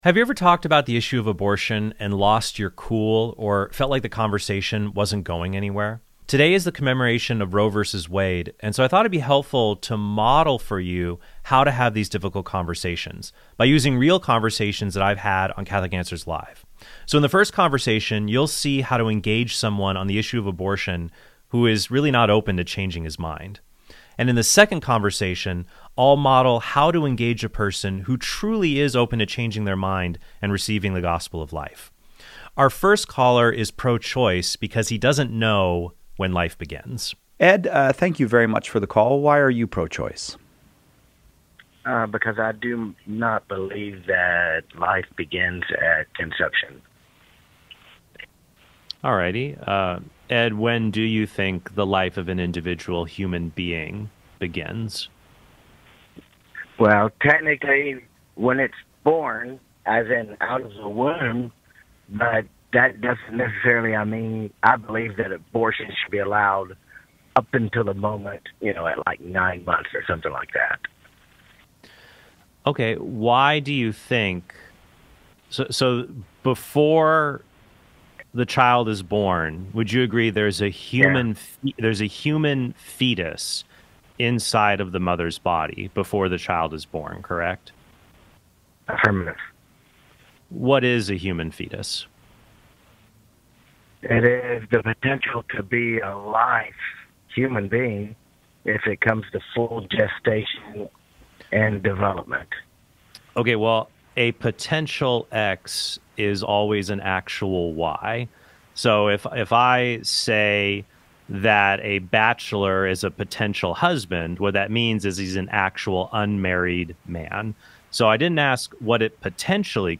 Today is the commemoration of Roe vs. Wade, and so I thought it’d be helpful to model for you how to have these difficult conversations by using real conversations that I’ve had on Catholic Answers Live.